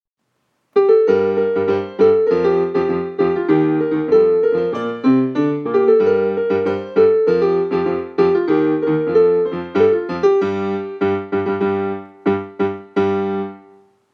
福岡ソフトバンクホークス #32 塚田正義 応援歌